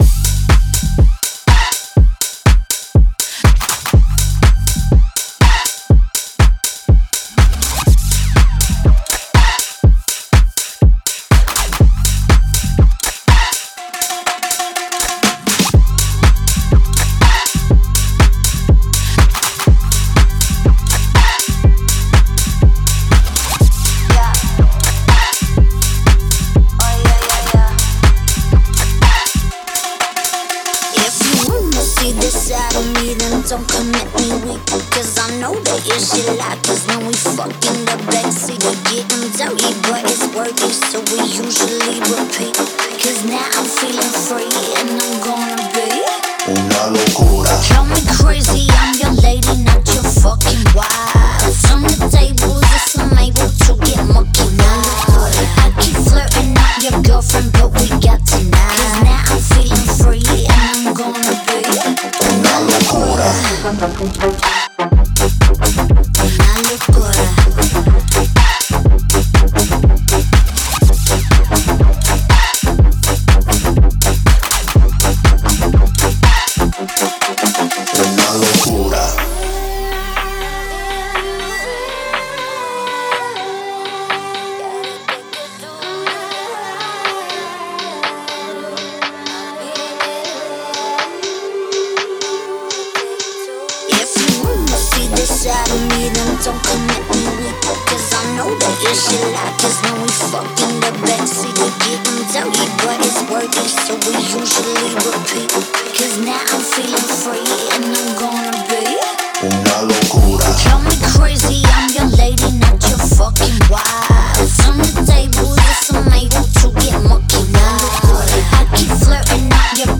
это энергичная поп-песня с элементами электронной музыки